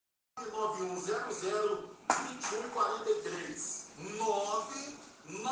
Na oportunidade, o caminhoneiro, que está revoltado com mais um aumento, fala de uma paralisação na cidade de Campanha.
caminhoneiro.mp3